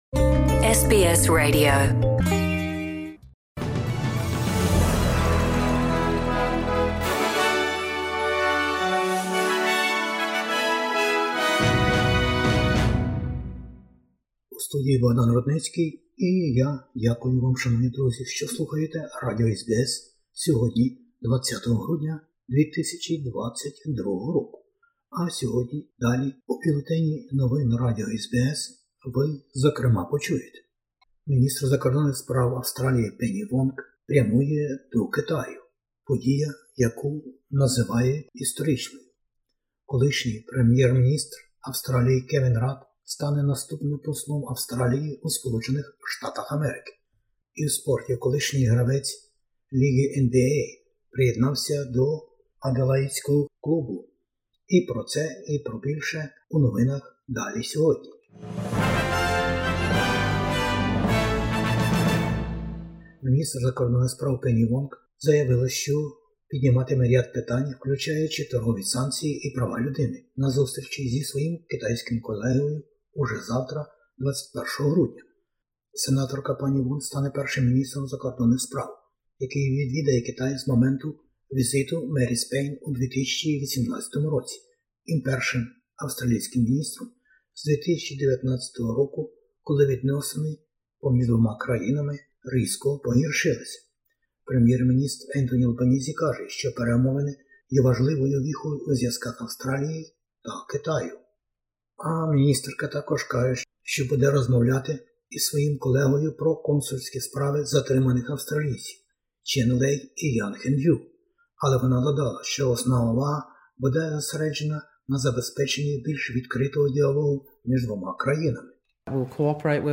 SBS news in Ukrainian - 20/12/2022